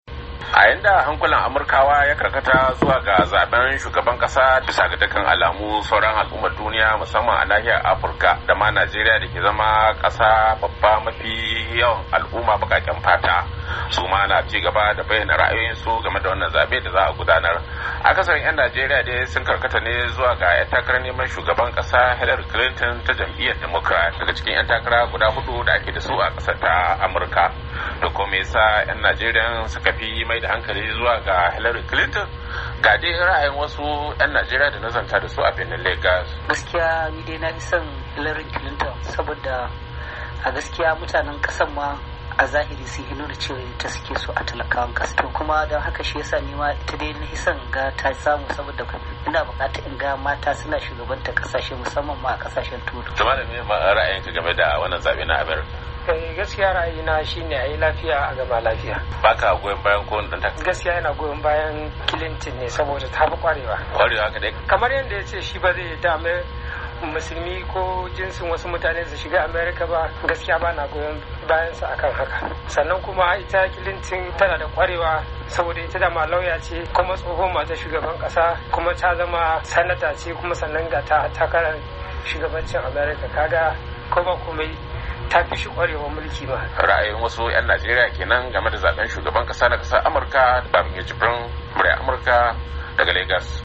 Muryar Amurka ta zagaya a cikin birnin Lagos, domin jin ra’yoyin mutane da kuma dalilan da yasa mutane suka mayar da hankali ga ‘yar takara Hillary Clinton.